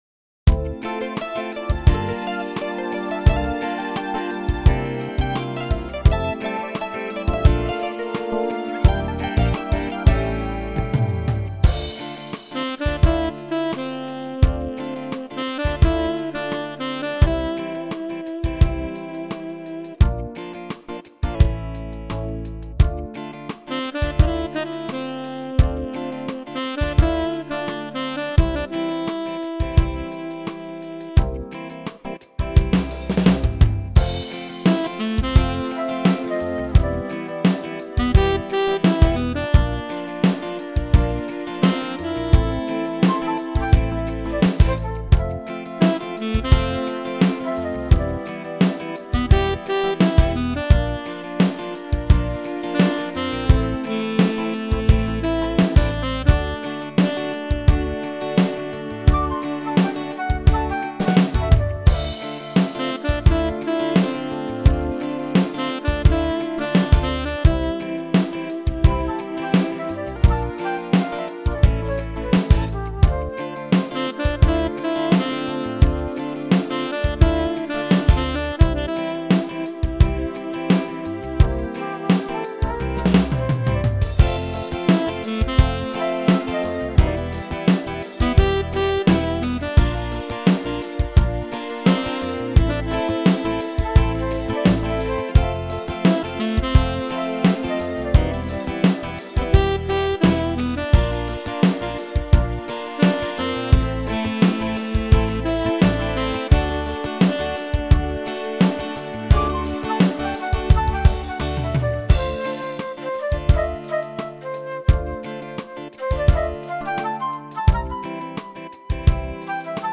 in a more upbeat, jazzier style.